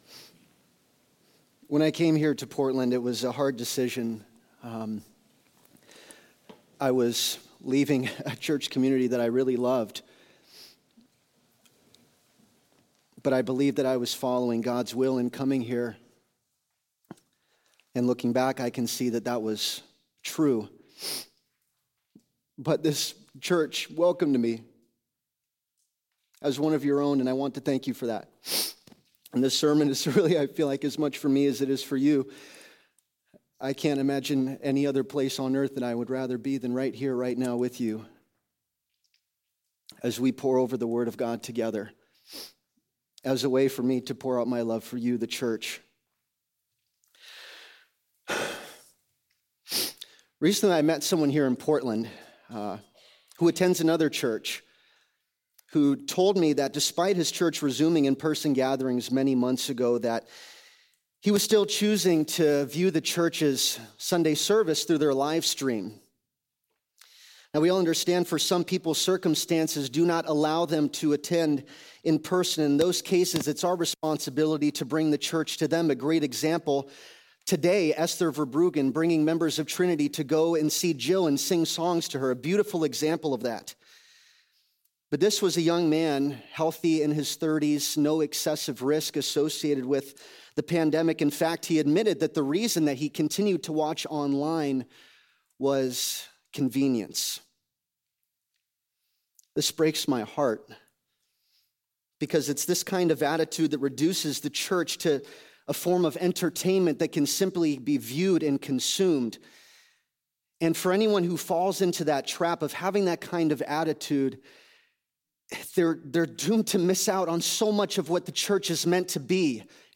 Trinity Church Portland